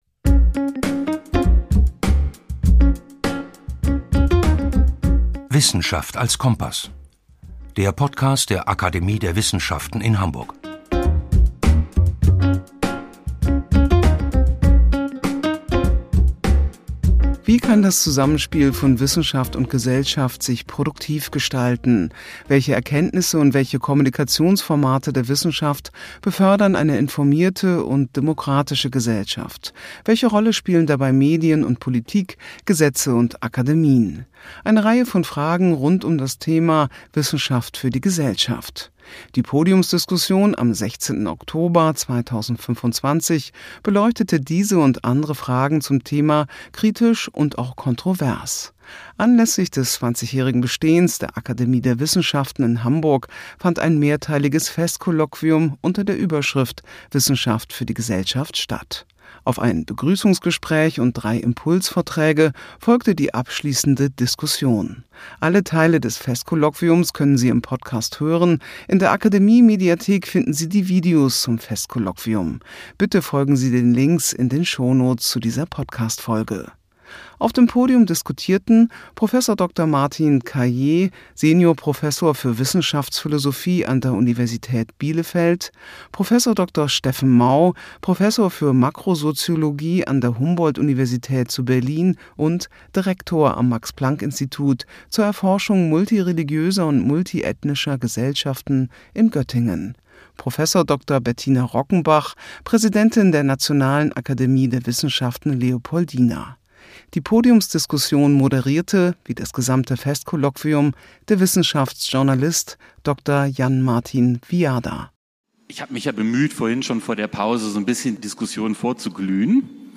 Podiumsdiskussion zum Festkolloquium 2025 ~ Wissenschaft als Kompass. Der Podcast der Akademie der Wissenschaften in Hamburg Podcast
Mitschnitt vom 16. Oktober 2025 im Lichthof der Staats- und Universitätsbibliothek Hamburg Carl von Ossietzky